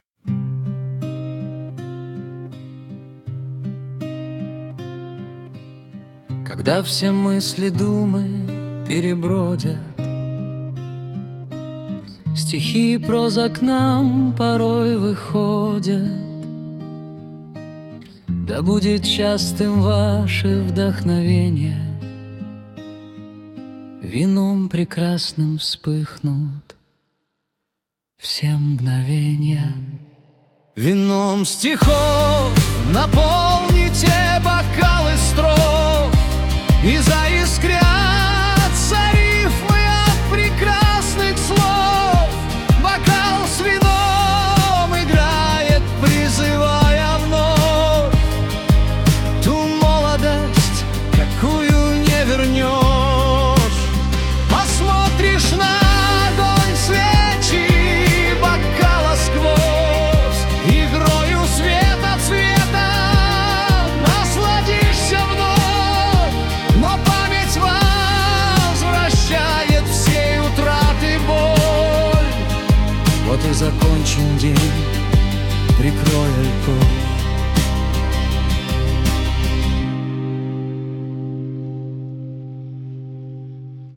• Статья: Лирика
Романс